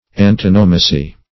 Antonomasy \An*ton"o*ma*sy\, n.